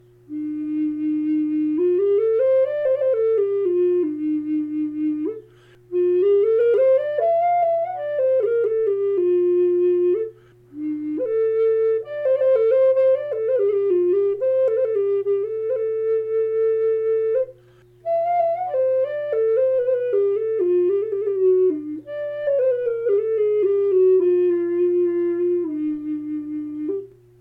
E4 in Wenge, carved to look and feel like a log; Black Walnut bear with a Turquoise inlaid heartline.